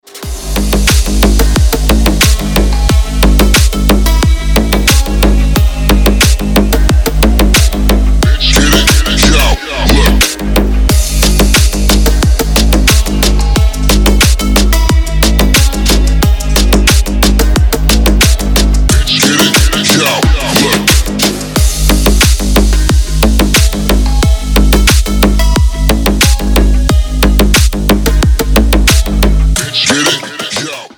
Клубные рингтоны
Клубная нарезка на вызов